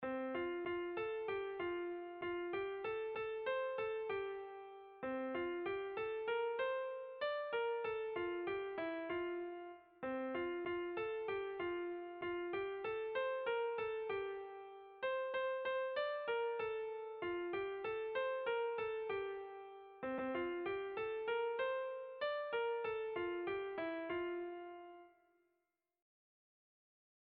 Erlijiozkoa
Hamarreko txikia (hg) / Bost puntuko txikia (ip)
A1BA1A2B